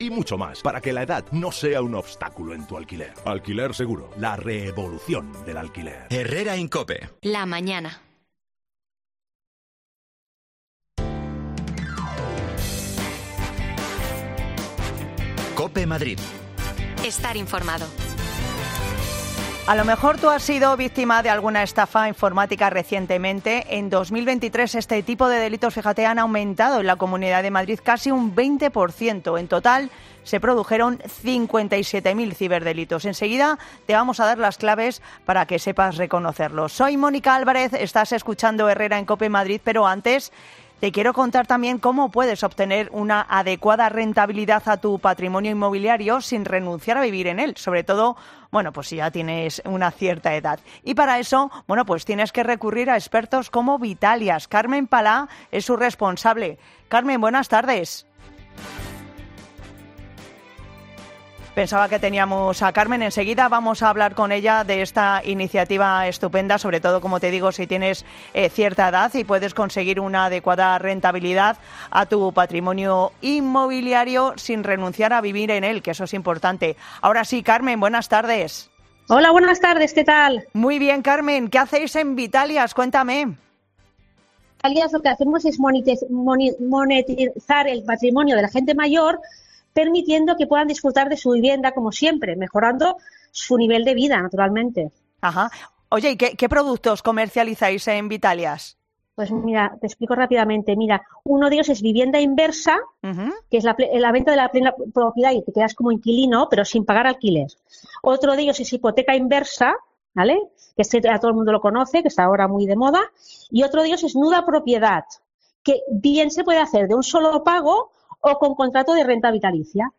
Hablamos con un experto para no caer en las redes de los ciberestafadores
Las desconexiones locales de Madrid son espacios de 10 minutos de duración que se emiten en COPE , de lunes a viernes.